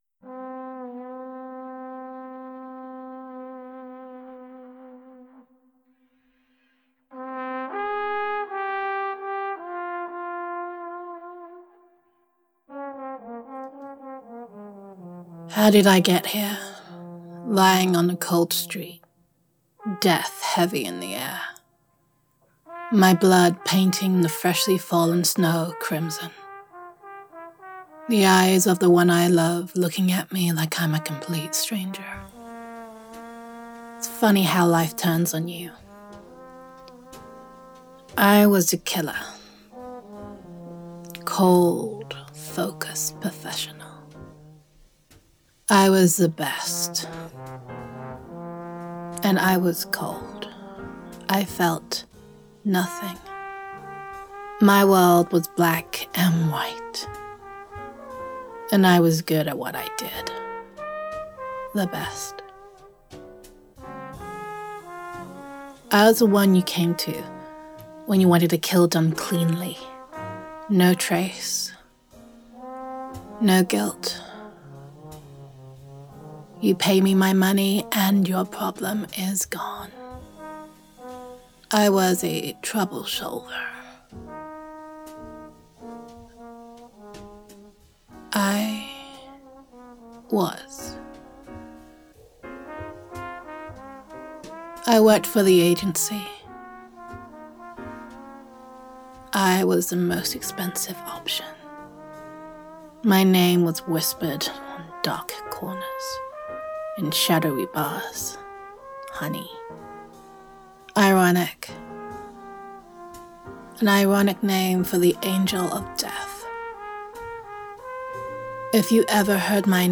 Downloads Download [F4A] The World Is Better in Colour [Film Noir][Moody Jazz][Wandering Accent][Tragic Heroine][.mp3 Content Cupid's arrow is less deadly than mine, but somehow it hurts more.
Emotional Tone or Mood: Thrilling, poignant, and suspenseful Content Type: Audio Roleplay Teaser: My career as an assassin taught me about targets, but loving you showed me about missing them.